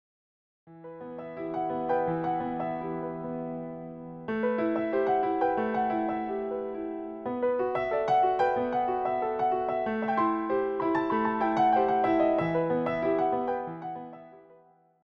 piano pieces